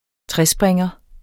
Udtale [ ˈtʁεˌsbʁεŋʌ ]